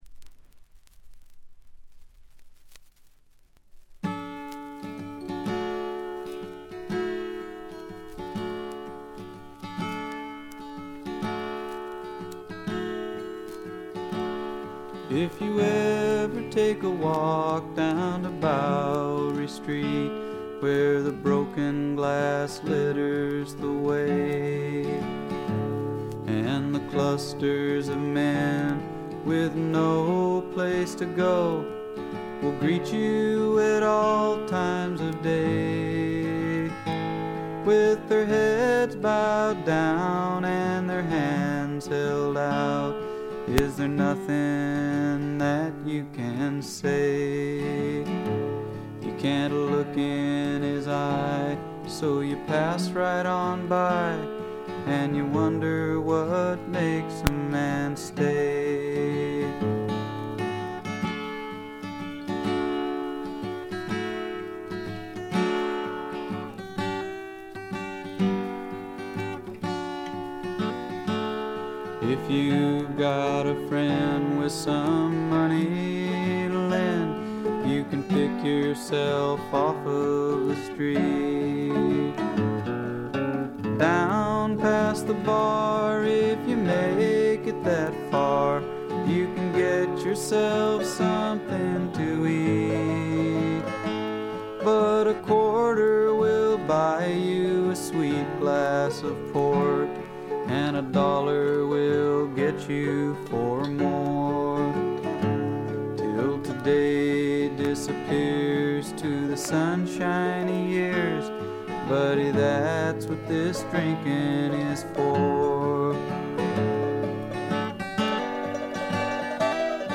A2中盤でプツ員の周回ノイズ10回ほど、B1序盤でプツ音2-3回ほど。
試聴曲は現品からの取り込み音源です。